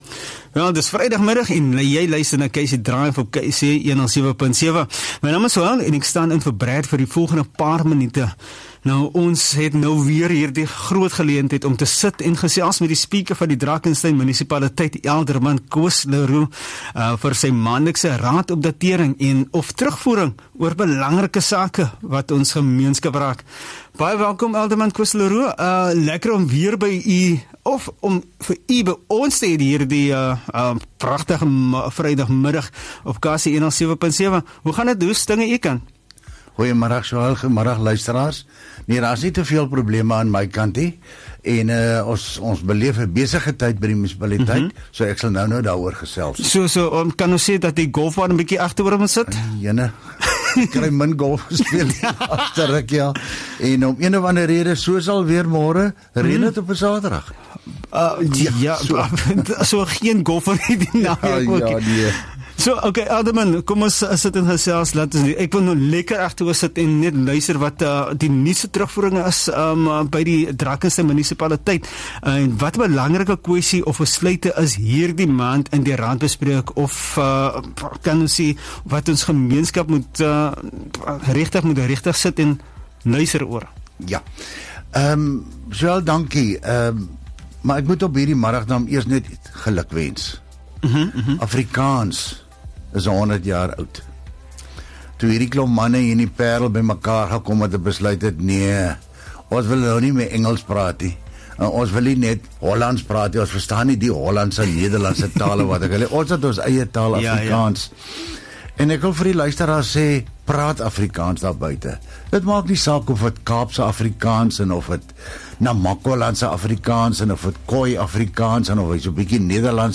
The Speaker of Drakenstein Municipality, Alderman Koos le Roux delivers his monthly update of the latest Council news.